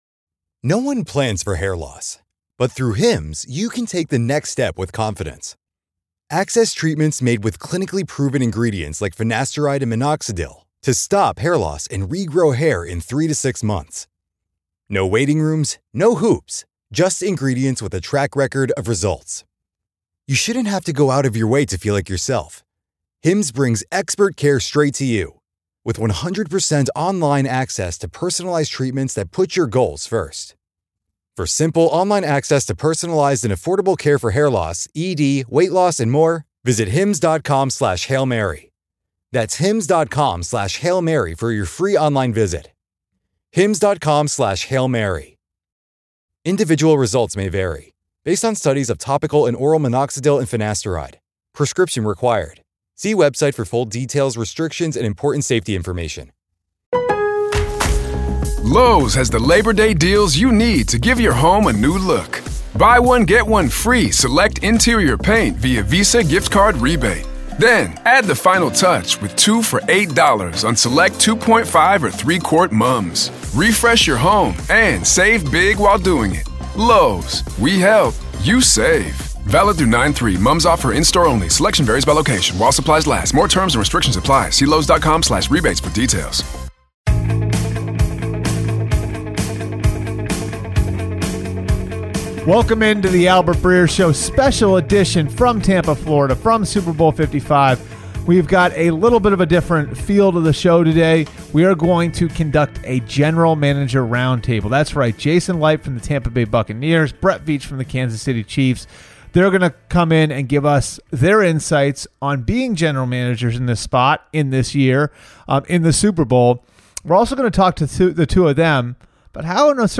GM Roundtable with Brad Veach & Jason Licht
Albert Breer talks to Tampa Bay Buccaneers GM Jason Licht & Kansas City Chiefs GM Brett Veach in the lead up to Super Bowl LV about their time together working for the Philadelphia Eagles, what makes Andy Reid a good coach, and what they look for in scouting new players